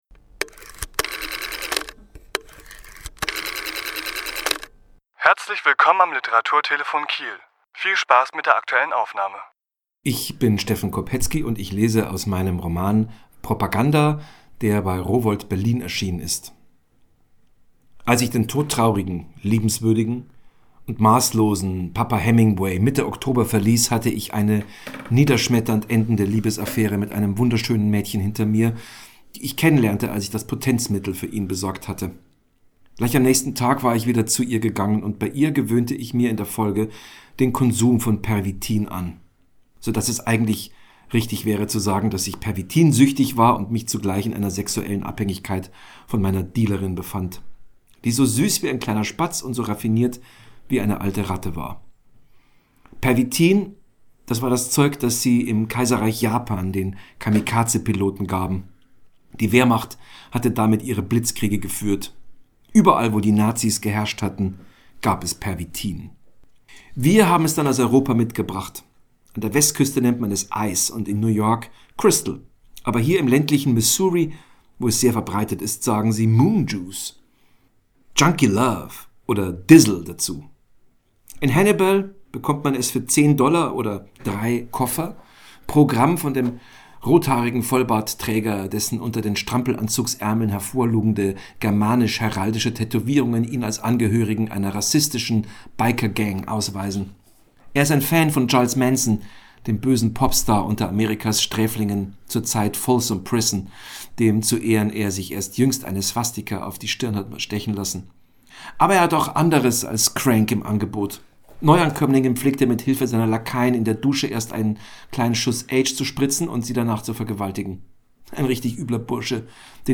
Autor*innen lesen aus ihren Werken
Die Aufnahme entstand im Rahmen einer Lesung im Literaturhaus S.-H. am 13.2.2020.